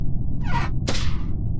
Among Us Vent In Sound Effect Free Download
Among Us Vent In